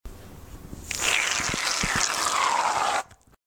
Chorro de líquido saliendo a presión
Grabación sonora que capta el sonido del chorro a presión del vaciado rápido del líquido de un recipiente por una boquilla pequeña.
Sonidos: Agua
Sonidos: Acciones humanas